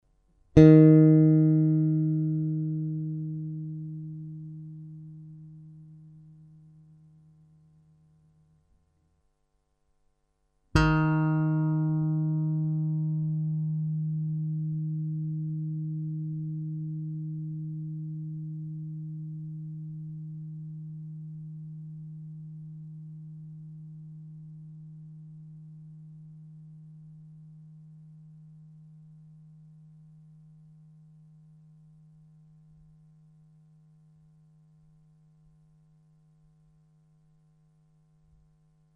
Na mém bývalém Jazz Bassu byly hned dva vedle sebe, oba na struně G.
Jeden byl na 7. pražci, čili tón D. V základním tónu D se ozývá ještě tón A na 440 Hz, který u dlouhého tónu nakonec převáží. Na konec nahrávky jsem oněch 440 Hz vygeneroval pro kontrolu.
Vlk na G na 7. pražci